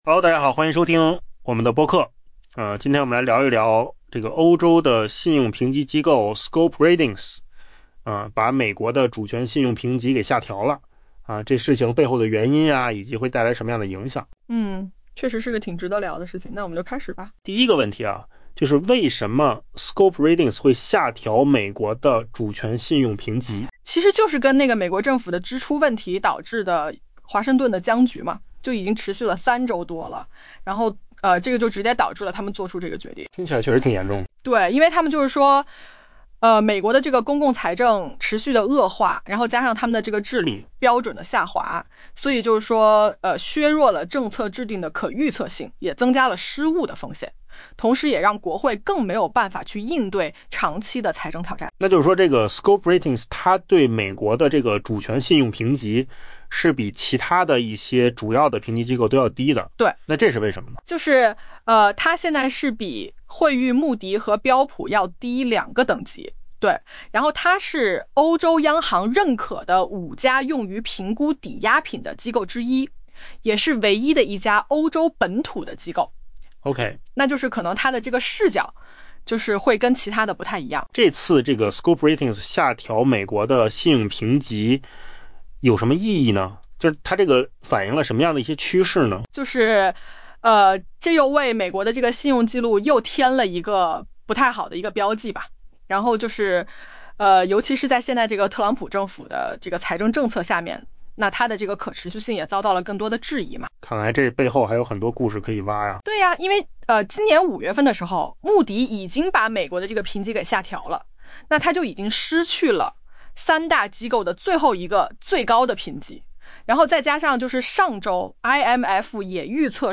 AI 播客：换个方式听新闻 下载 mp3 音频由扣子空间生成 美国政府支出问题引发的华盛顿僵局已持续三周多，欧洲信用评级机构 Scope Ratings 因此将美国主权信用评级下调一级。